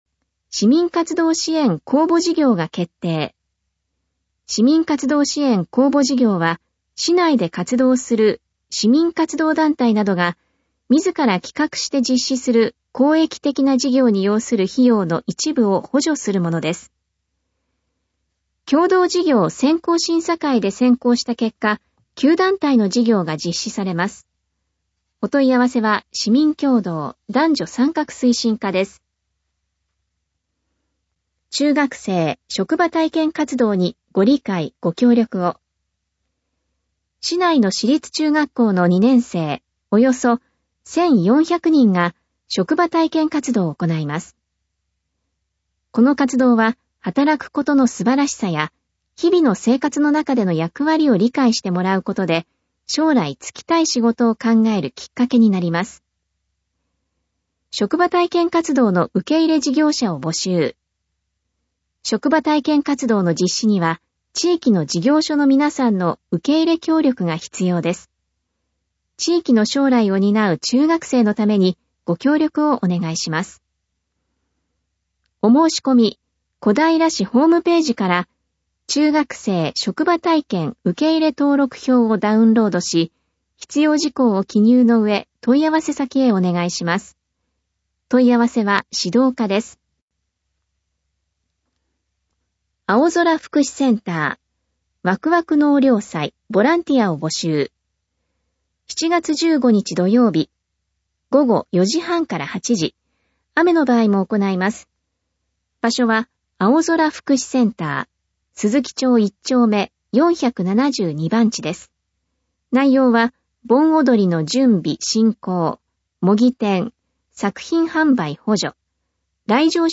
市報音声版「声のたより」2017年6月5日号｜東京都小平市公式ホームページ